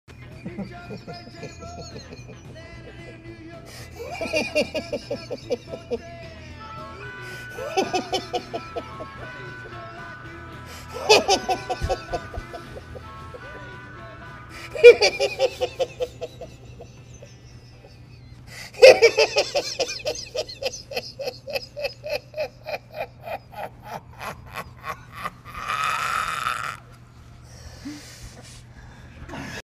Letterkenny Coach Laugh